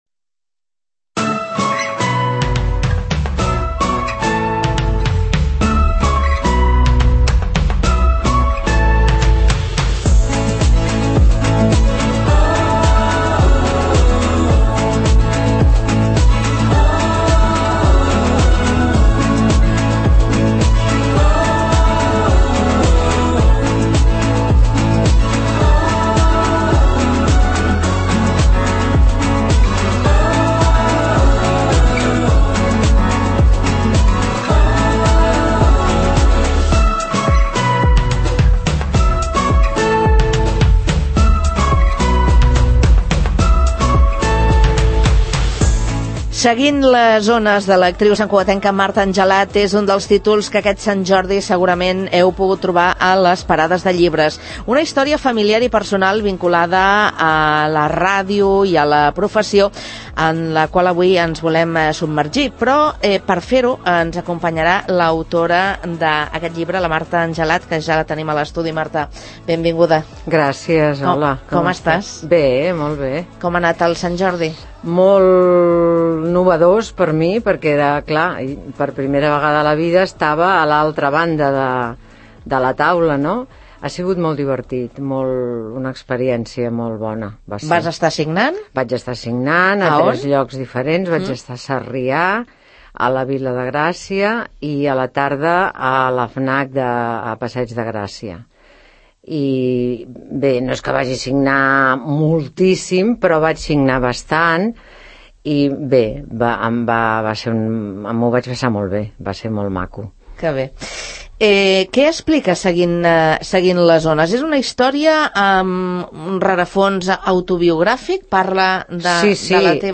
com ha reconegut l'autora en una entrevista al programa 'Connectats'.